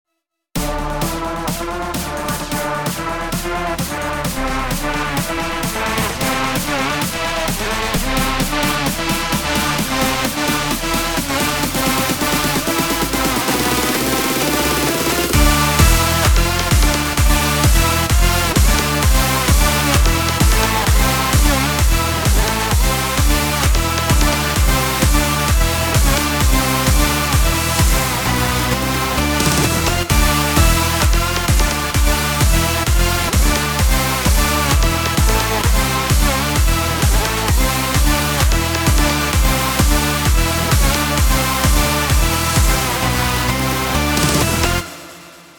טראקים שבניתי בטוירוס 5
יפה מאוד קצת משעמם זה לא מספיק מלא אבל זה דרופ של החיים בהצלחה
יש שם איזה פד באנגלית ועוד איזה בס אבל זה קצת ריק
נחמד, אין שום מלודיה, אבל מיקס מצוין